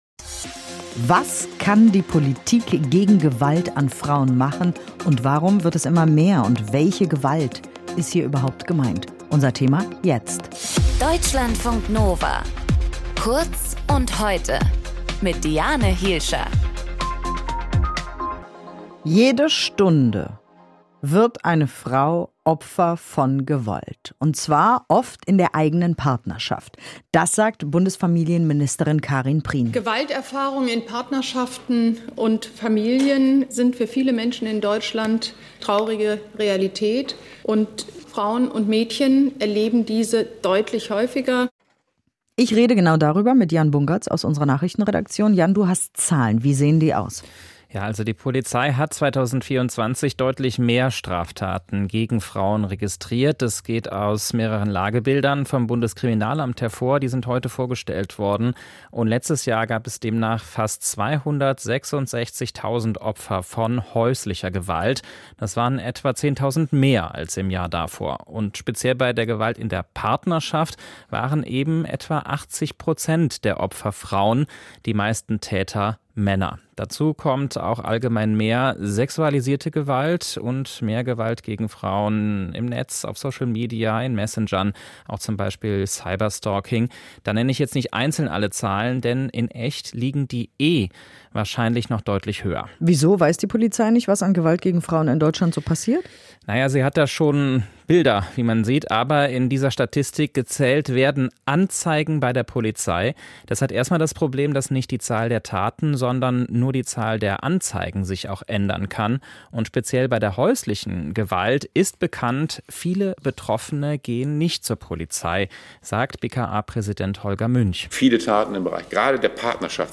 Moderation
Gesprächspartner